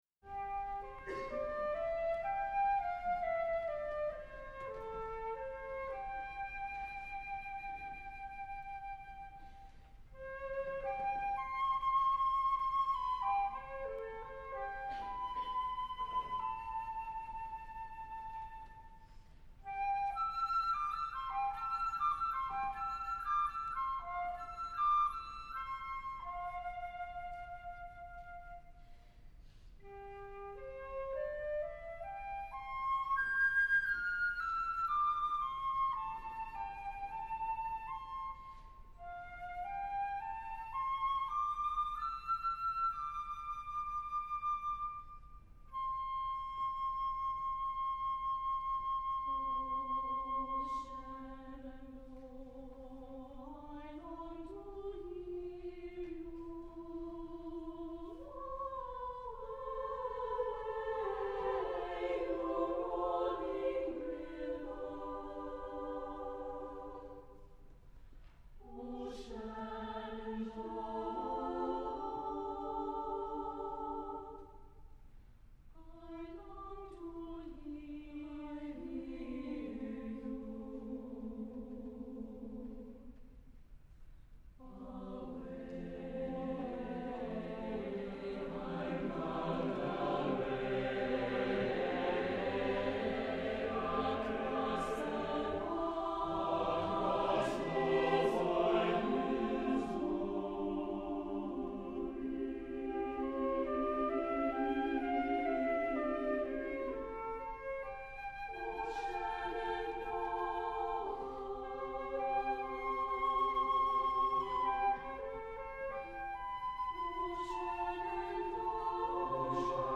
for SATB Chorus and Flute (1992)